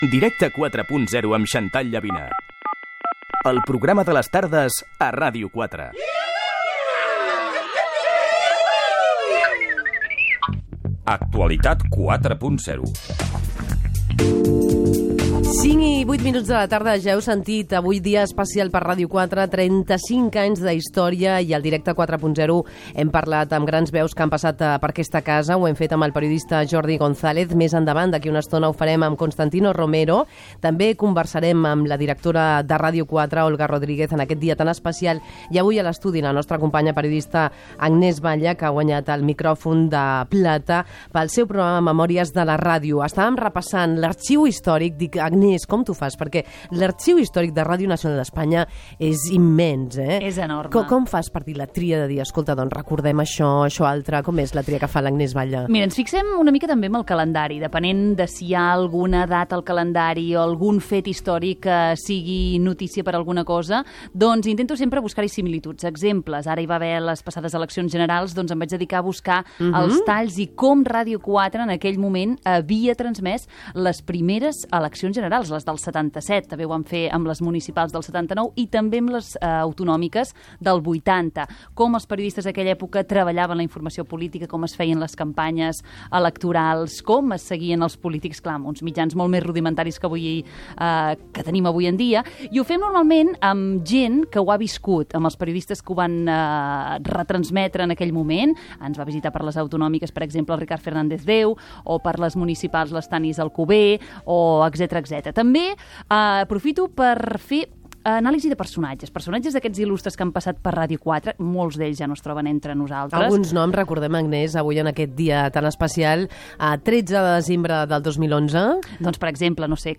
Indicatiu del programa.
S'inclouen diversos fragments de l'inici de Ràdio 4, com la transmissió de l'arribada a Catalunya del President de la Generalitat Josep Tarradellas.
Entreteniment